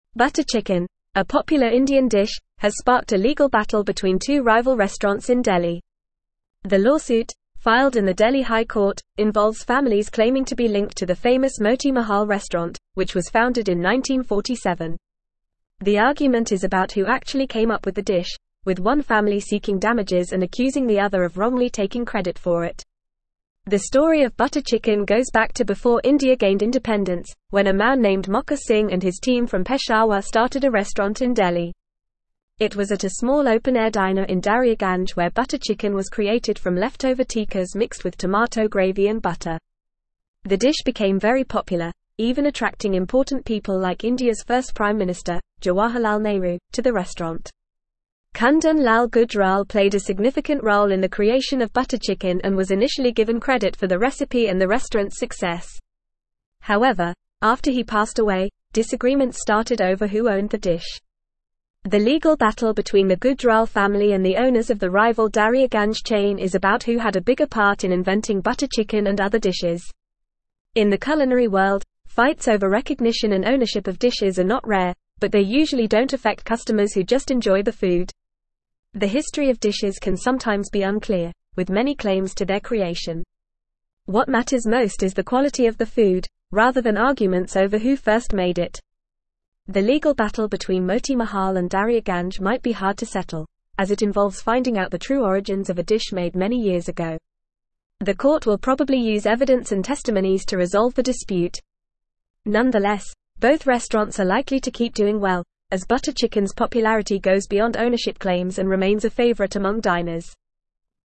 Fast
English-Newsroom-Upper-Intermediate-FAST-Reading-Butter-Chicken-Origins-Delhi-Restaurants-in-Legal-Battle.mp3